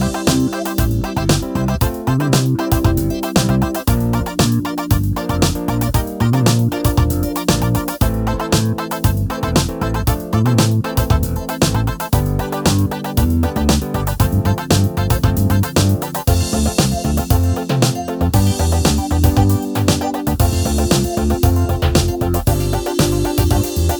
no Backing Vocals Soul / Motown 5:45 Buy £1.50